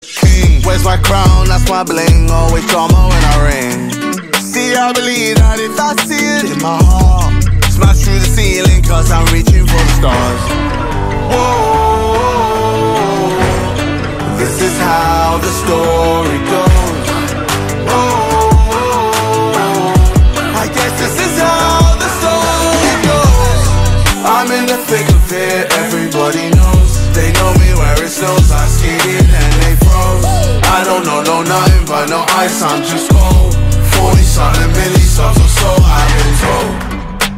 Kategorie Rap/Hip Hop